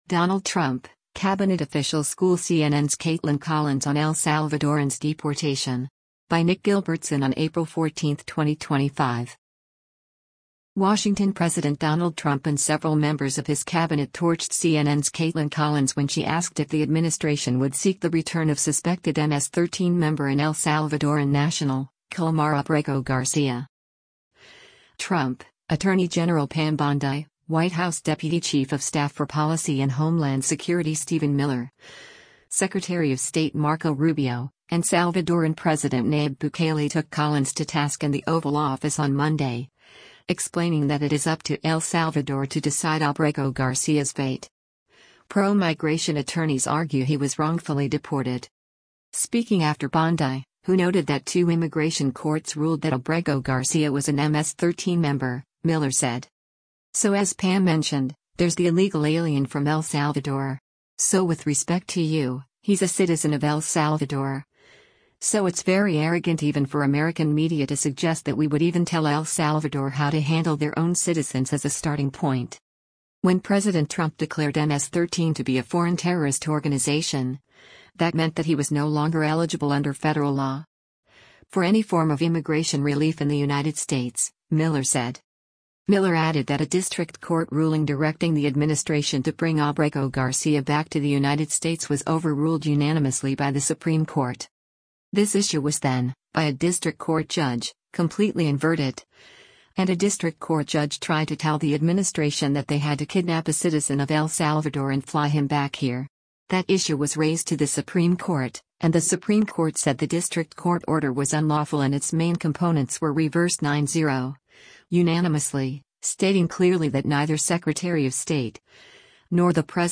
Trump, Attorney General Pam Bondi, White House Deputy Chief of Staff for Policy and Homeland Security Stephen Miller, Secretary of State Marco Rubio, and Salvadoran President Nayib Bukele took Collins to task in the Oval Office on Monday, explaining that it is up to El Salvador to decide Abrego Garcia’s fate.